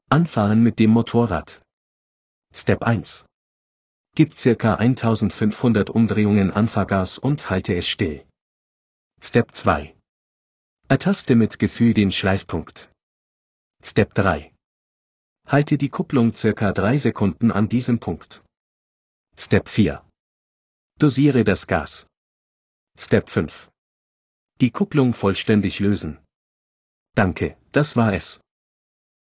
Bike-Anfahren.m4a